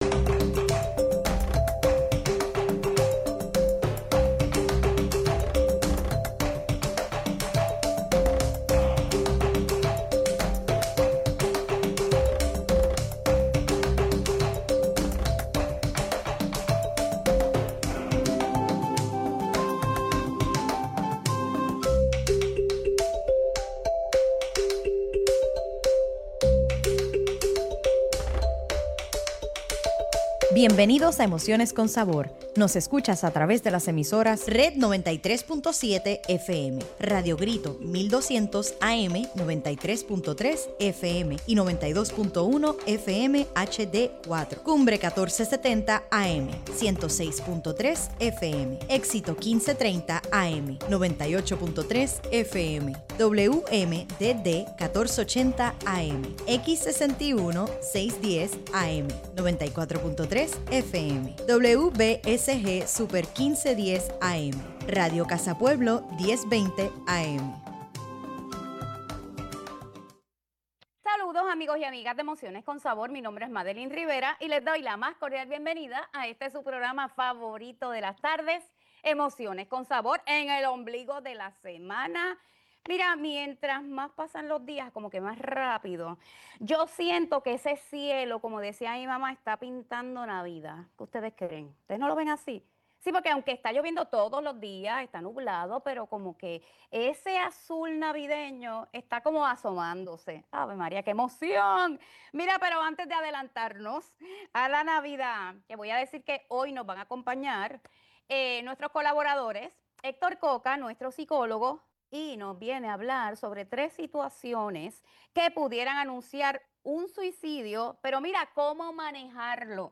En vivo en nuestros estudios con el tema: ¿Cómo desarrollar la empatía?